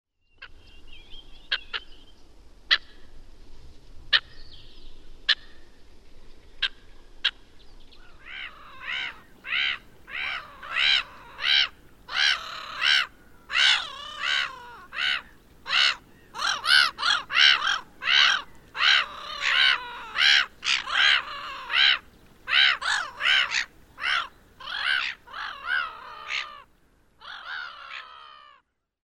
Naurulokilla on erilaisia kirkuvia ääniä